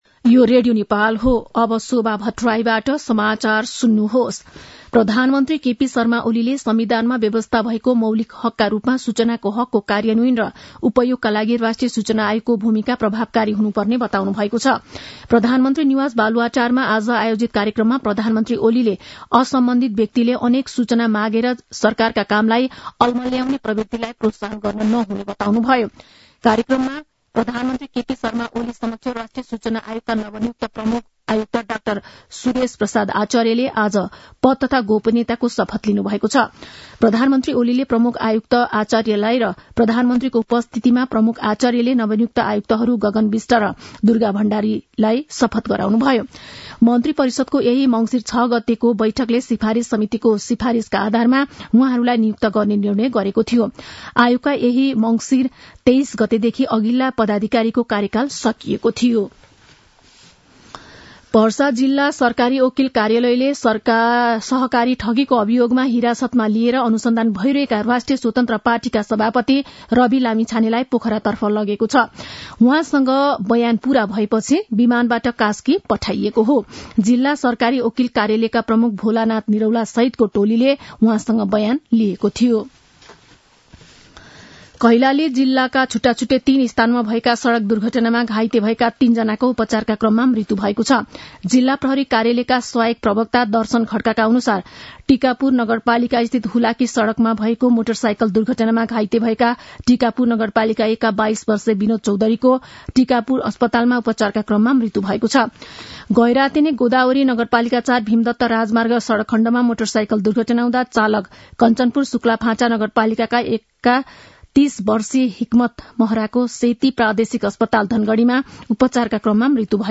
मध्यान्ह १२ बजेको नेपाली समाचार : २७ मंसिर , २०८१
12-am-nepali-news-1-8.mp3